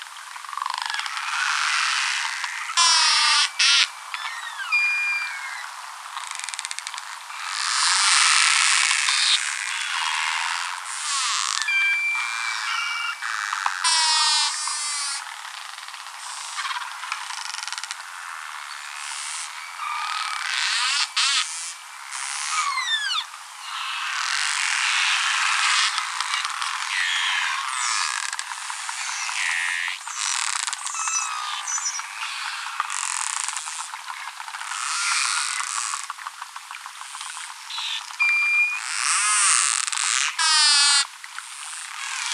Nom commun : Le narval
Nom latin : Monodon monoceros